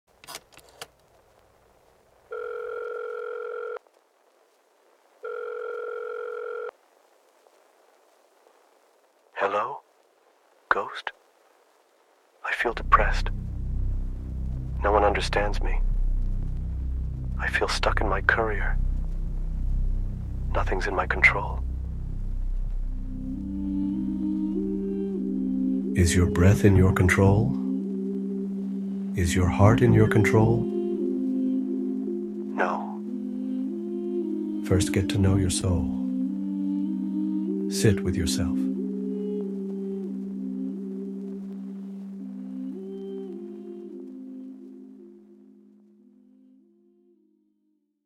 Punjabi Music Album